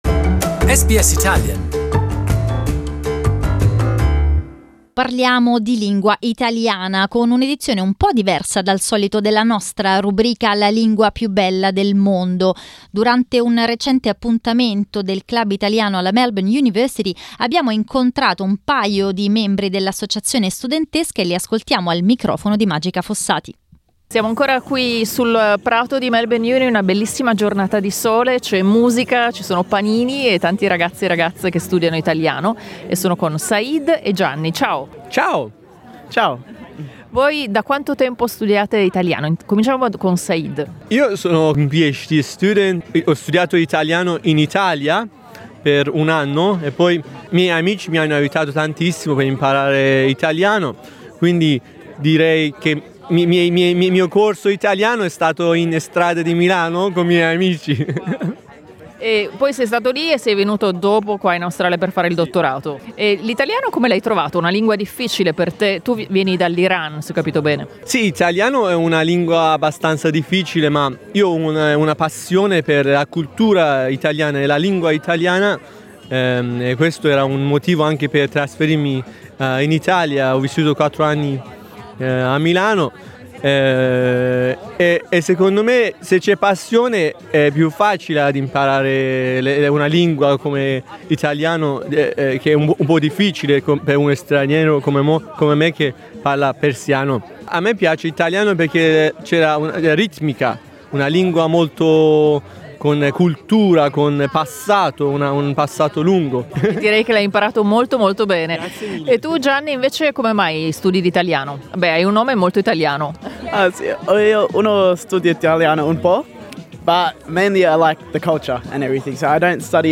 Durante un recente appuntamento del Club Italiano della Melbourne University abbiamo incontrato alcuni membri dell'associazione studentesca.